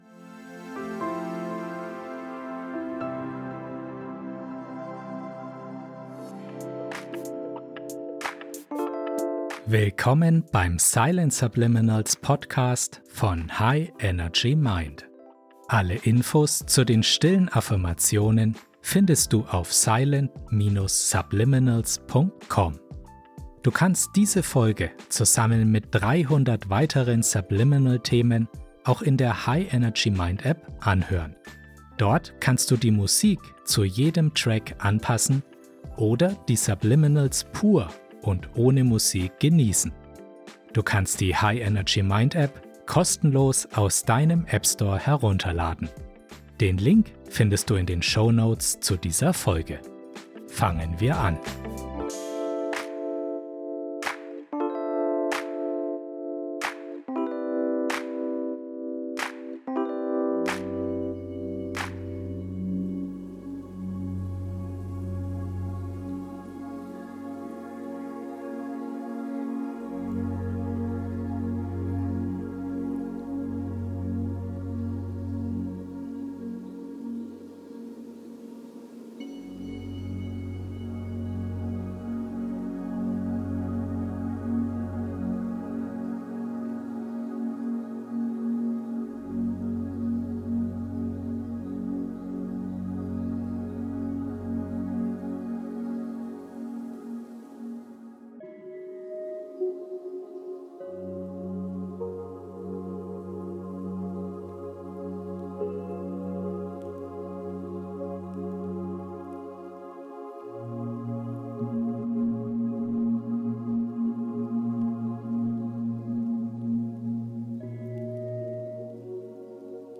harmonische Entspannungsmusik mit der Frequenz von 432 Hz